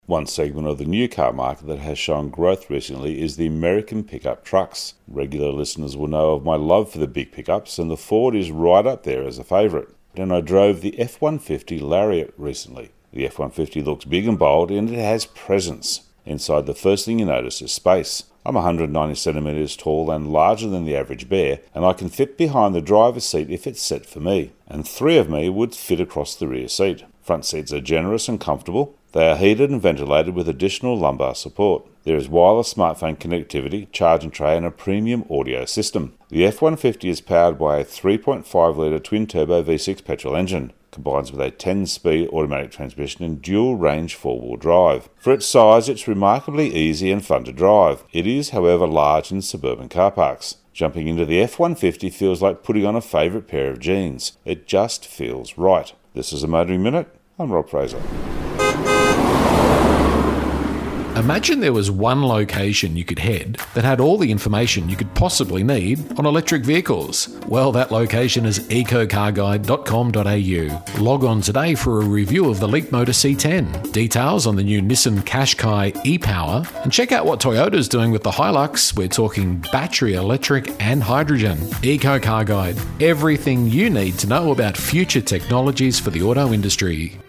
Ford F-150 Lariat road test review